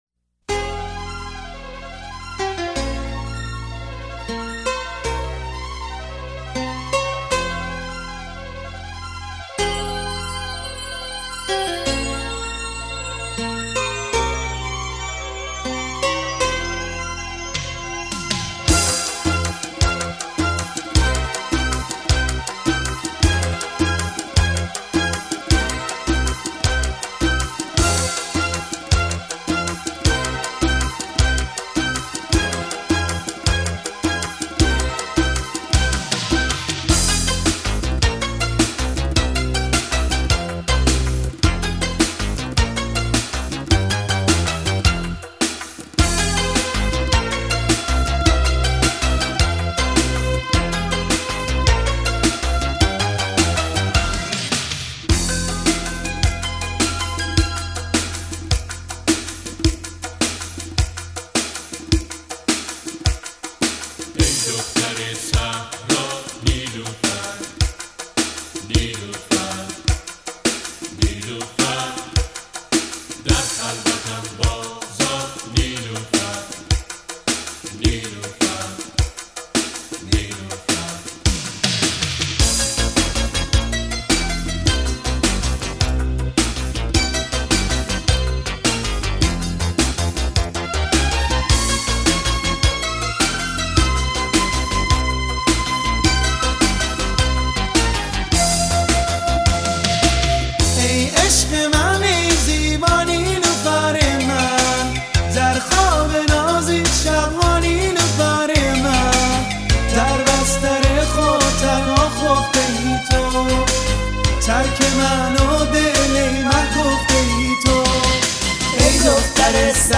Музыка / Иранские / Поп / Ретро / Прочее / Альбом